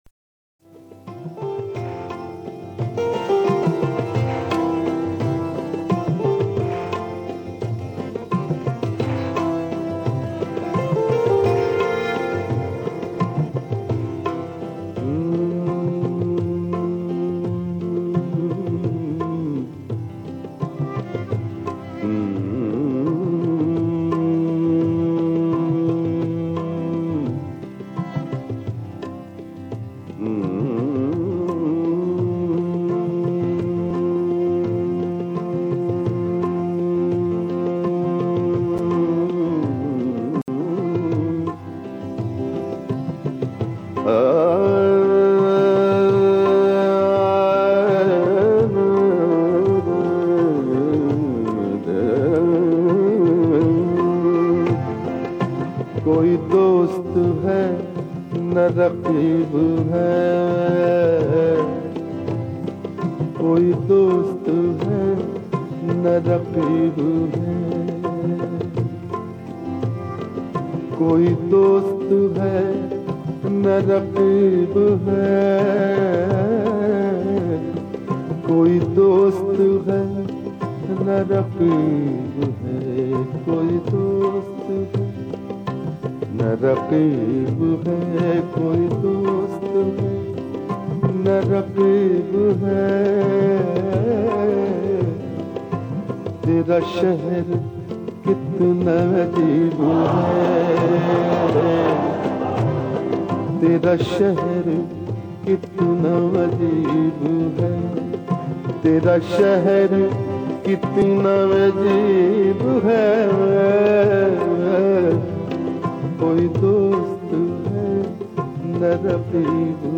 ghazal
soulfully sung